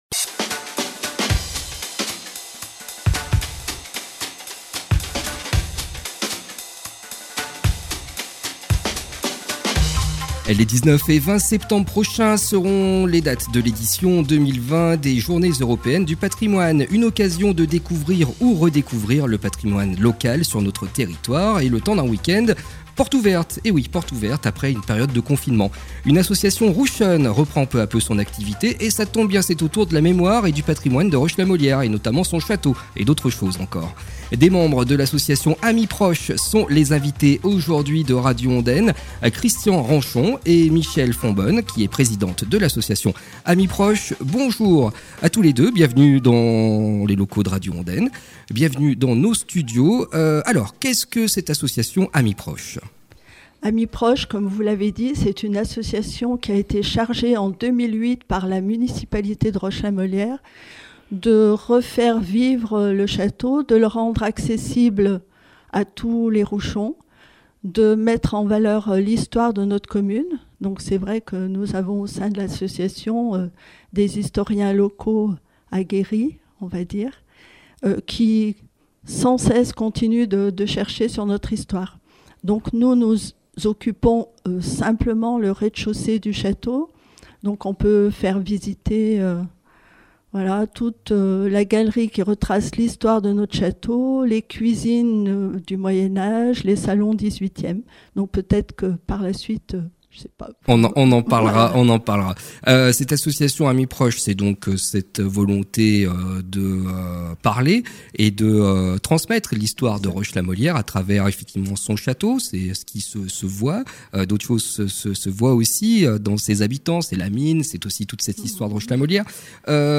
AMIPROCHE, Journées Européennes du Patrimoine 2020, Roche la Molière. – Radio Ondaine 90.9 FM